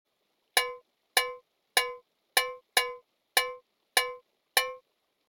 Moor_Cowbell_Sound.mp3